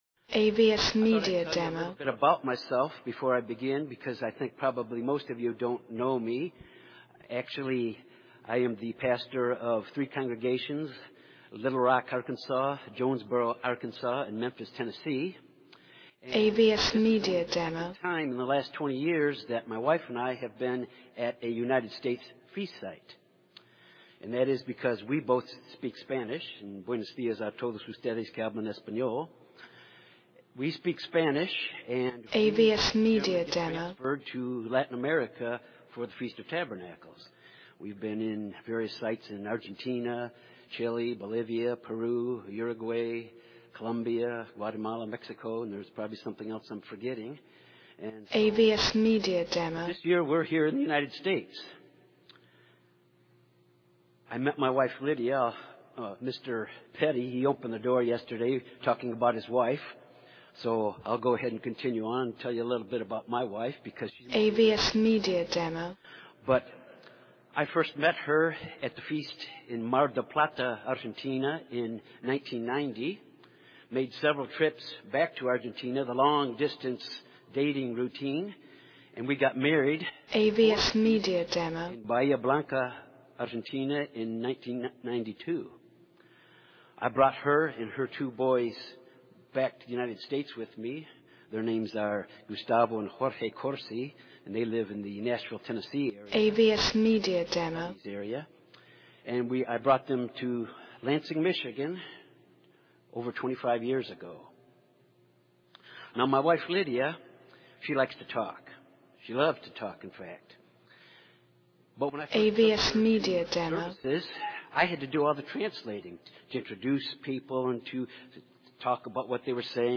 This sermon was given at the Galveston, Texas 2018 Feast site.